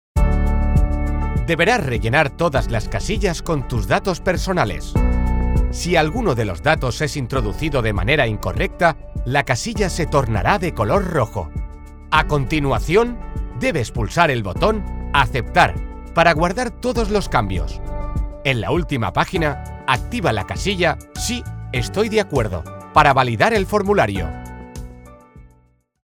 I have mi own home studio in which I do the work in the shortest possible time.
My vocal color is professional, youth, warm, persuasive and friendly.
Sprechprobe: eLearning (Muttersprache):
I am a professional neutral Spanish voice artist.